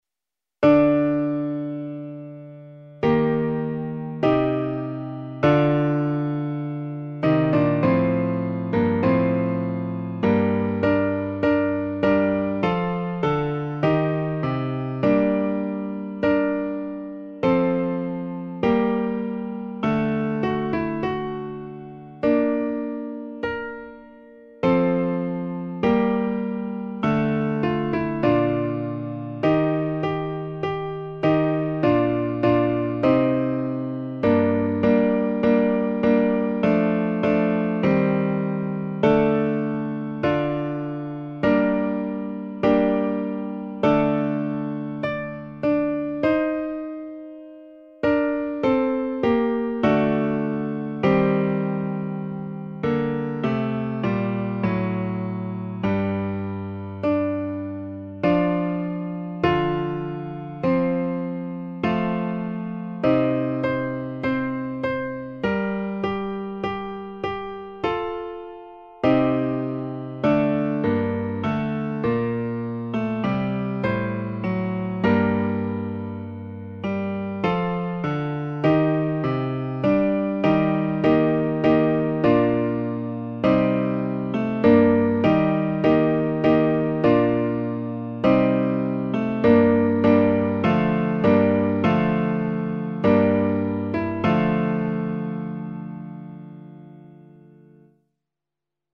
Versión en Re m (un tono bajo)
A 4 voces (Soprano, Alto, Tenor y Bajo).